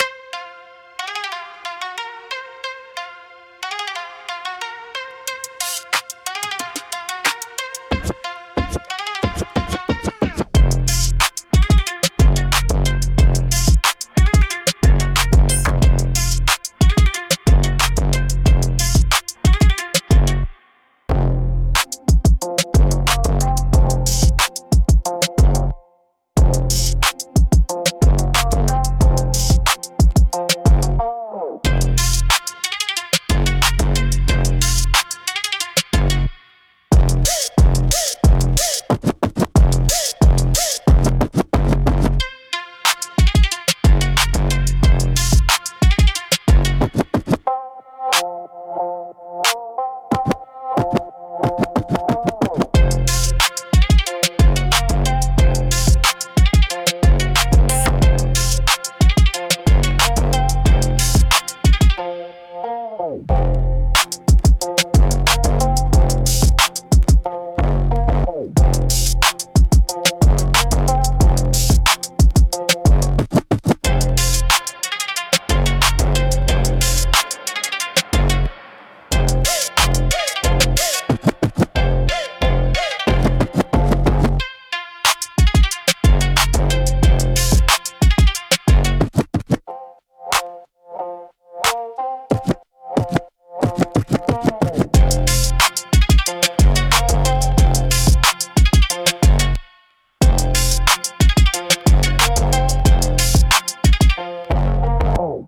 Instrumental - Crown & Consequences